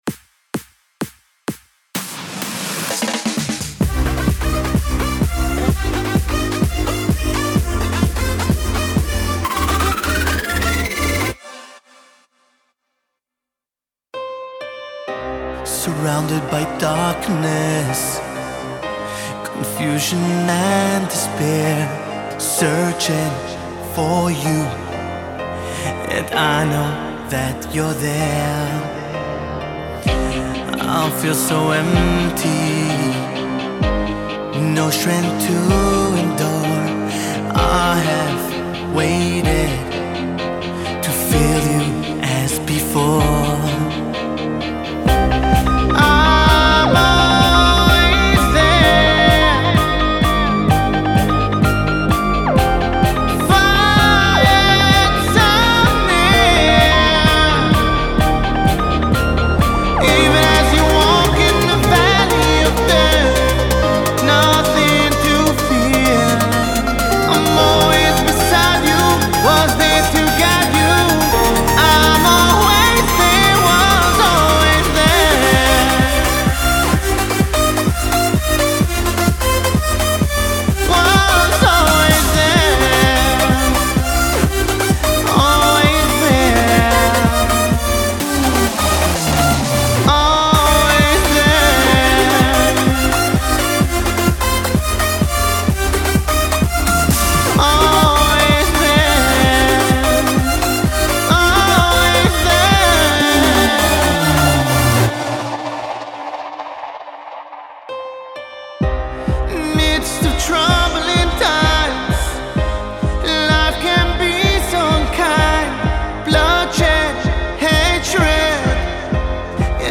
מלודיה מרוממת